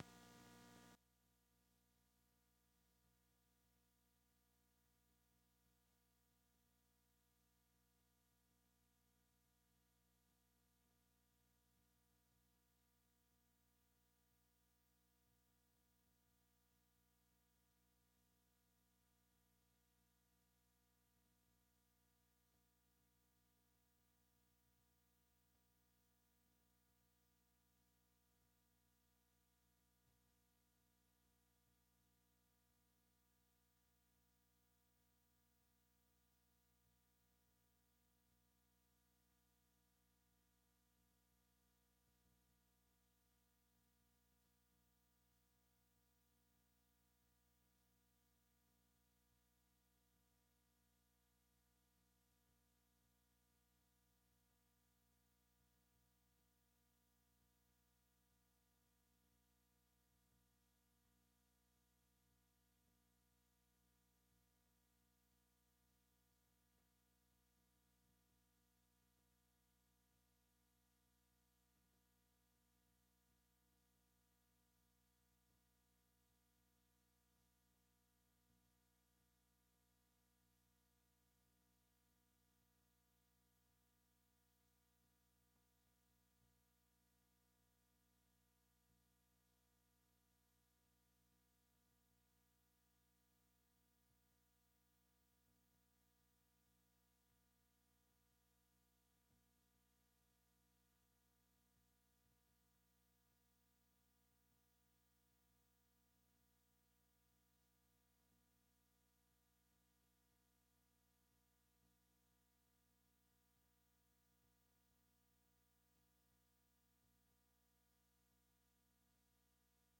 講道經文：《馬太福音》Matthew 22:1-14 本週箴言：《以弗所書》Ephesians 4:1-3 「既然蒙召，行事為人就當與蒙召的恩相稱。